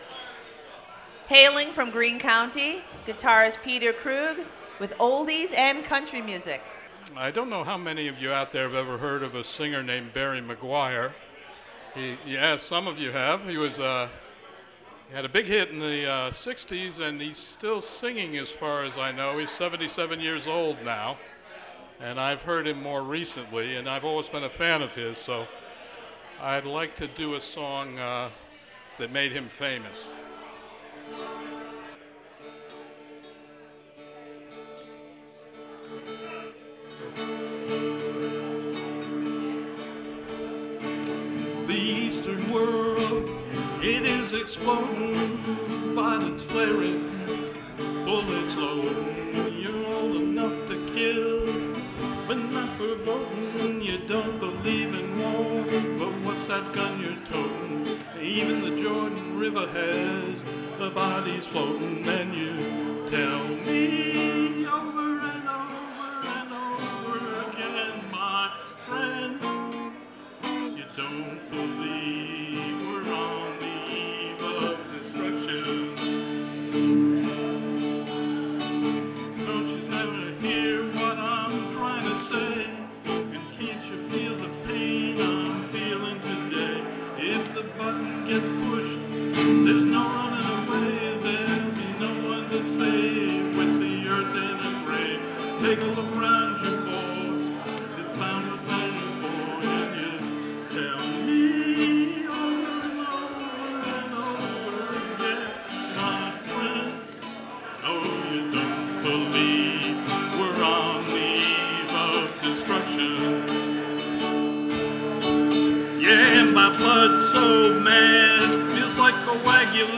at WGXC 90.7-FM Spring Forward Open Mic at Crossroads Brewing
Recorded from WGXC 90.7-FM webstream.